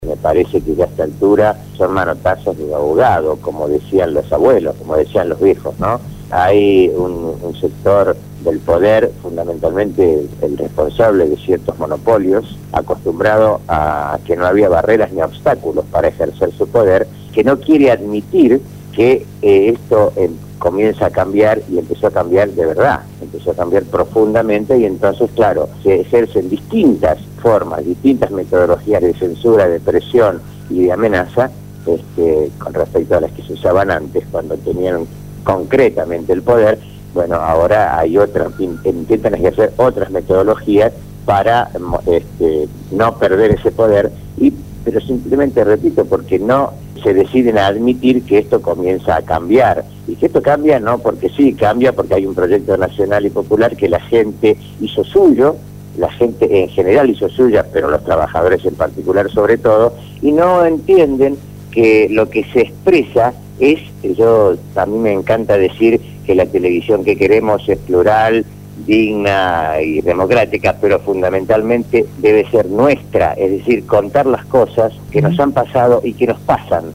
La  entrevista fue realizada en el programa Punto de Partida de Radio Gráfica FM 89.3